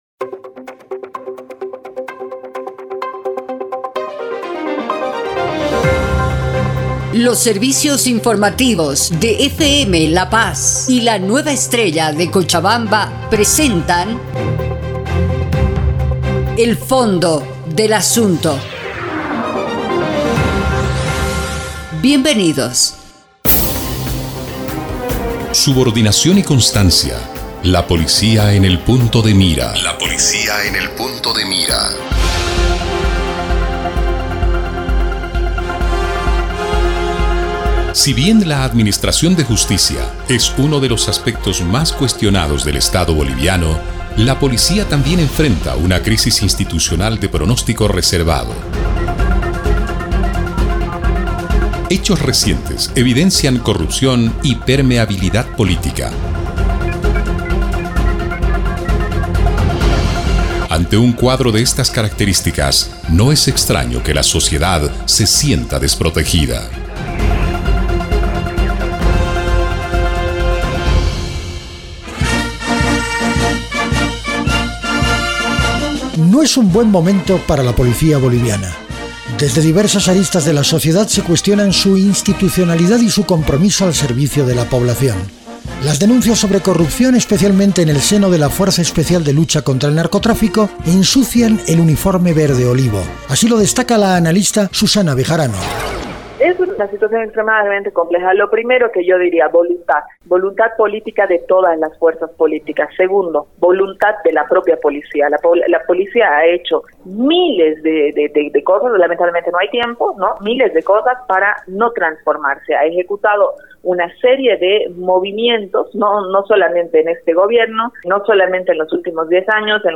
Un programa de reportajes